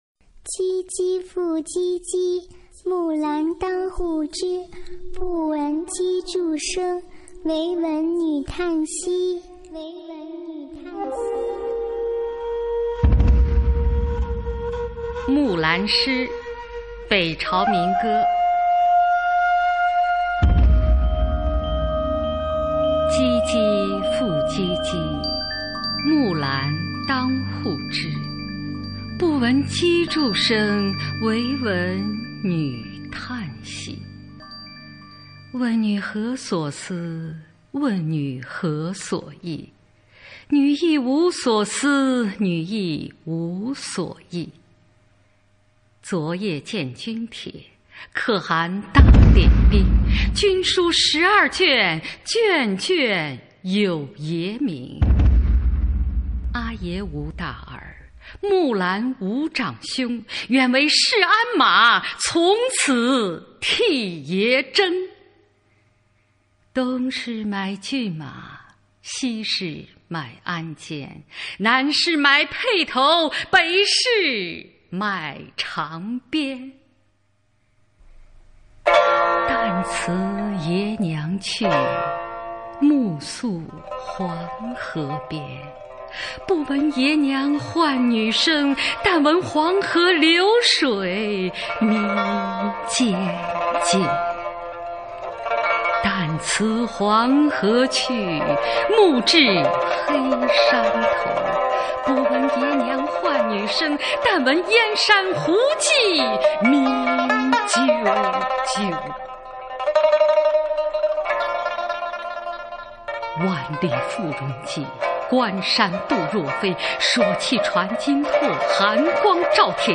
木兰诗 乐府民歌 经典朗诵欣赏群星璀璨：中国古诗词标准朗读（41首） 语文PLUS